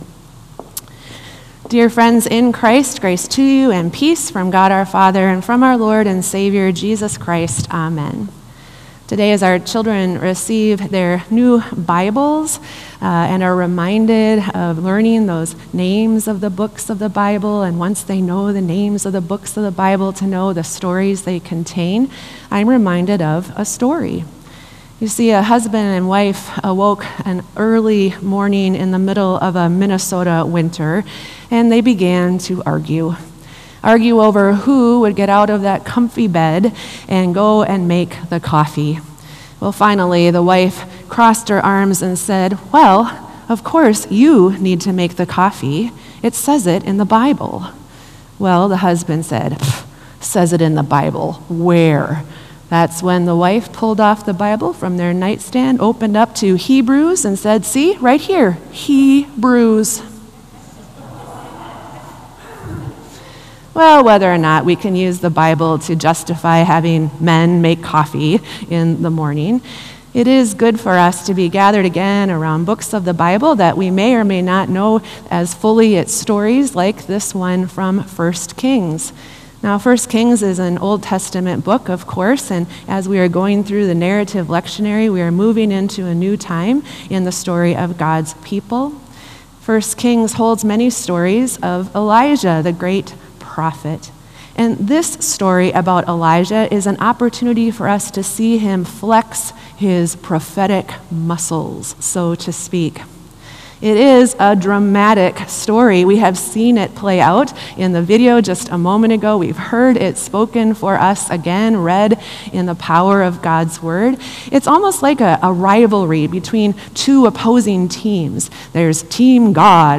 Sermon “The Showdown”